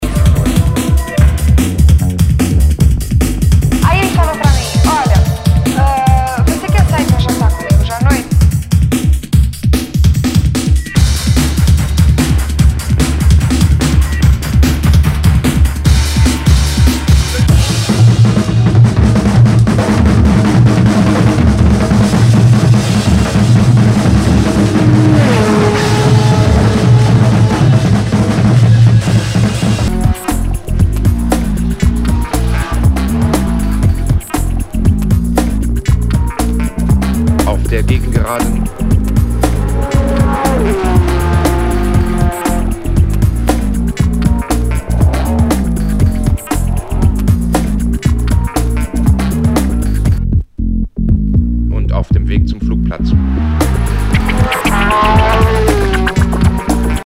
Nu- Jazz/BREAK BEATS
ナイス！ファンキー・ブレイクビーツ！
全体にチリノイズが入ります。
[VG-] 傷や擦れが目立ち、大きめなノイズが出る箇所有り。